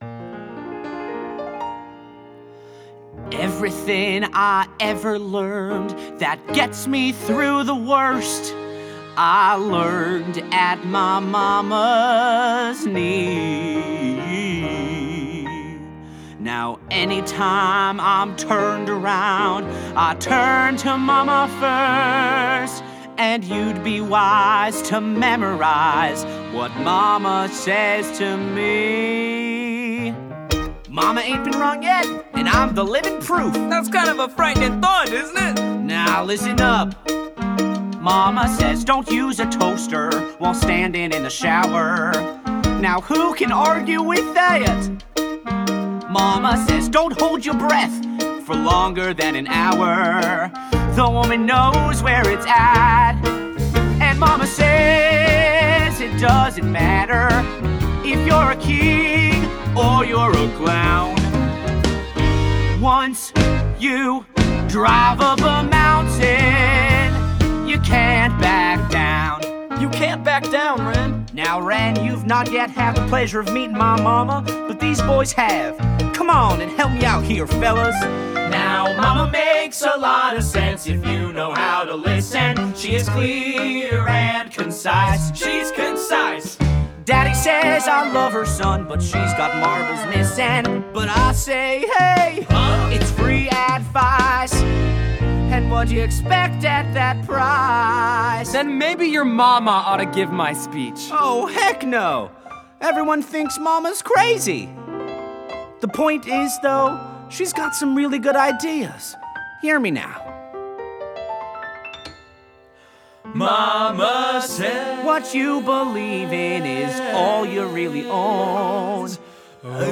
Willard, Ensemble